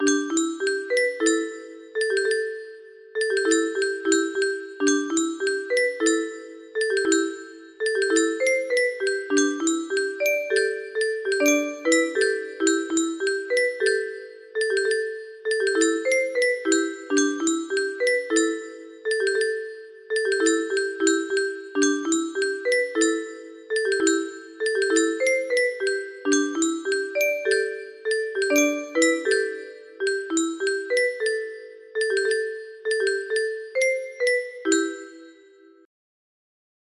Kikkerland 15 music boxes More